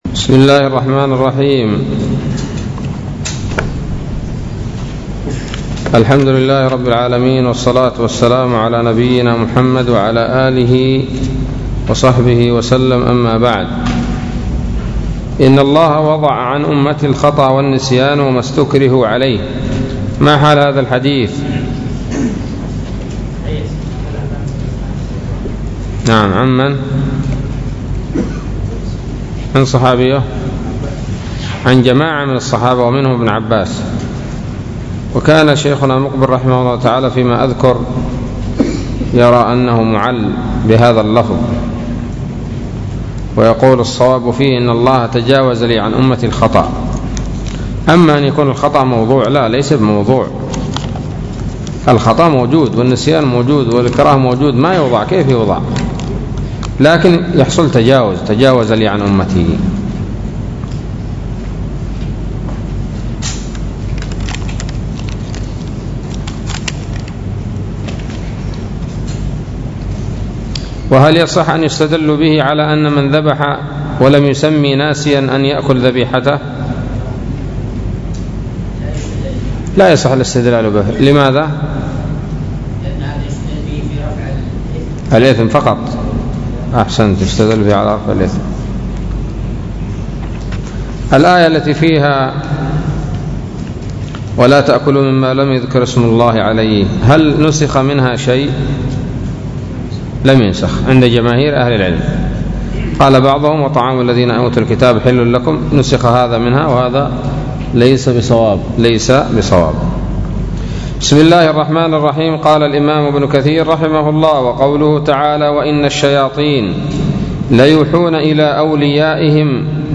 الدرس الرابع والأربعون من سورة الأنعام من تفسير ابن كثير رحمه الله تعالى